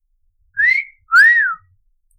Play, download and share 口哨 original sound button!!!!